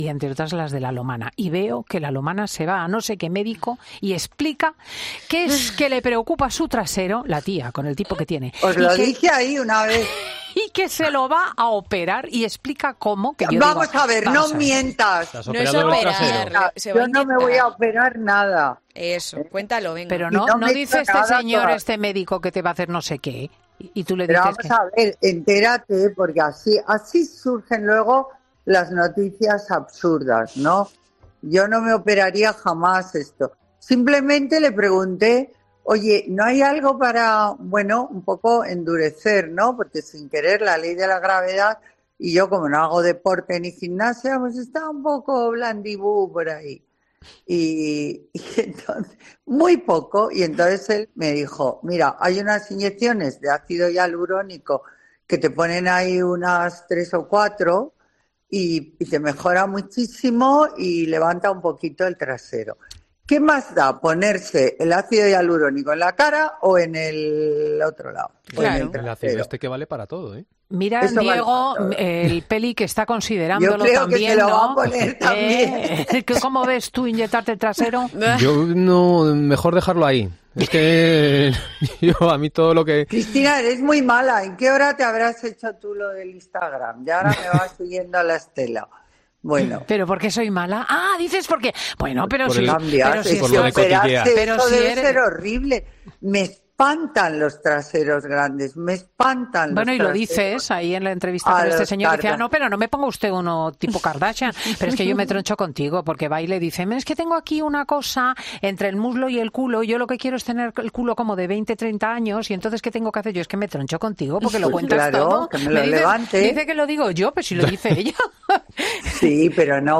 Y es que la socialité ha explicado cómo quiere que sea este retoque estético y cómo puede tenerlo perfecto "para pasar el verano", arrancando las carcajadas de todo el equipo.